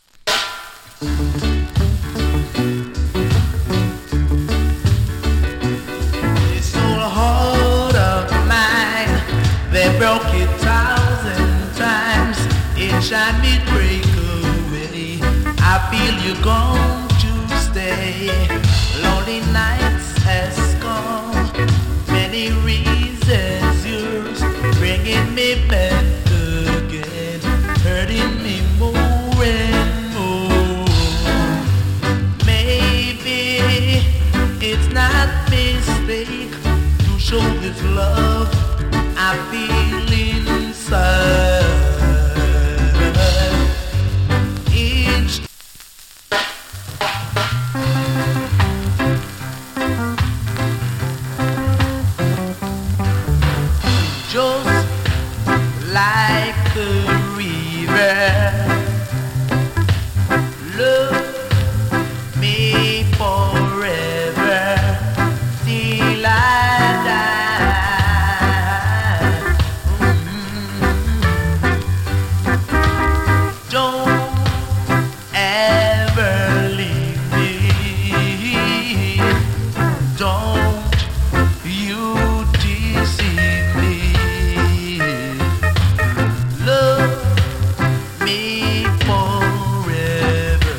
チリ、パチノイズ少し有り。
LATE 60'S NICE VOCAL ROCK STEADY !! 周期的に少し SMALL HISS が入ります。